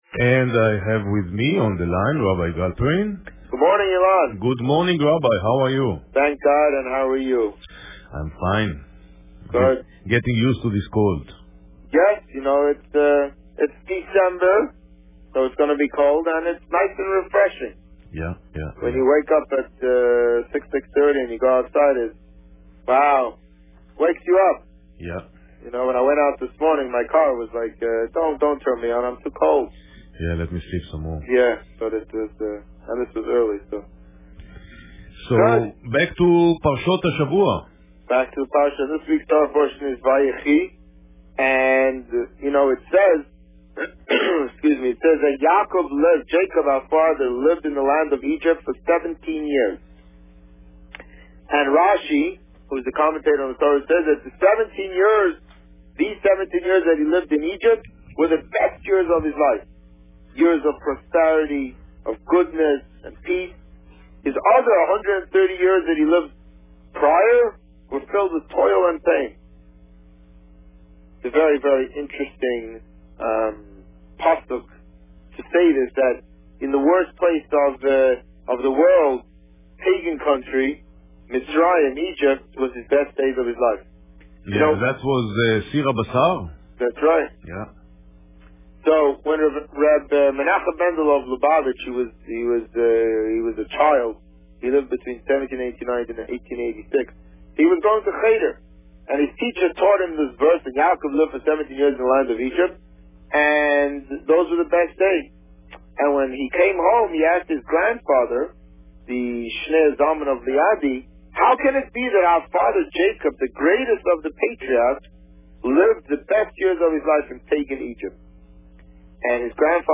The Rabbi on Radio